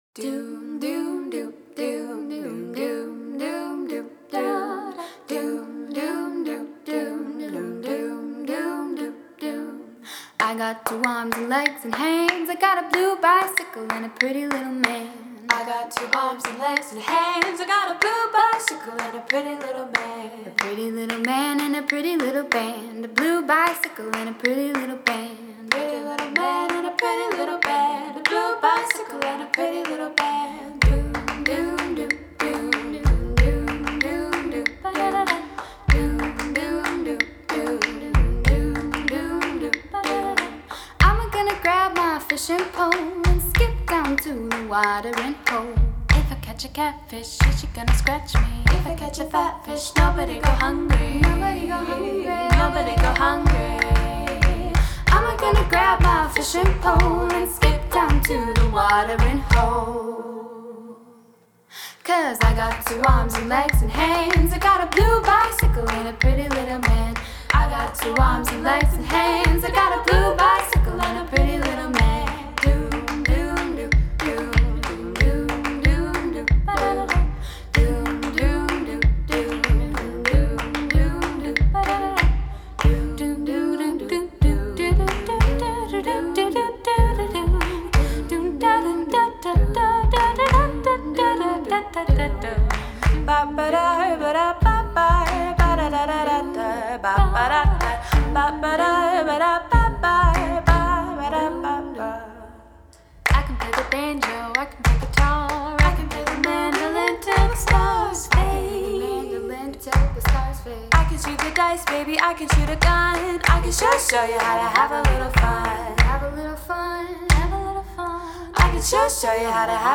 superbes parties vocales, harmonisées avec finesse
nous délivrant une chanson a capella des plus entraînantes